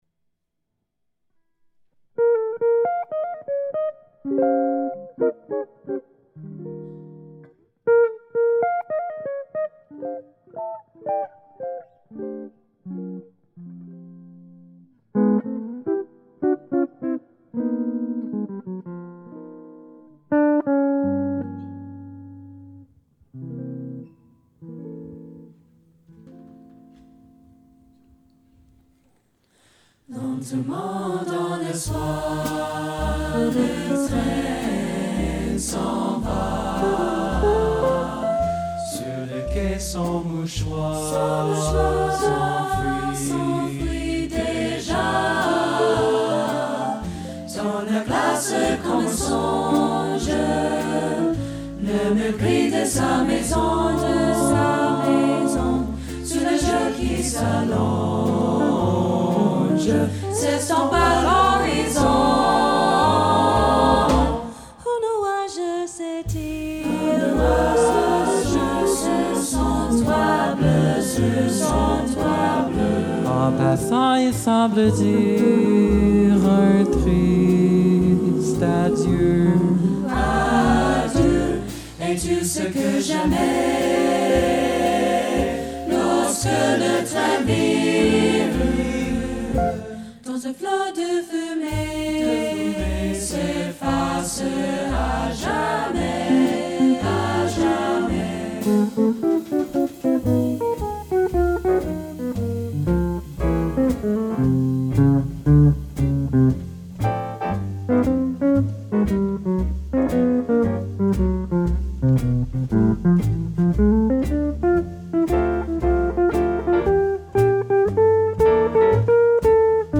SATB – piano, basse & batterie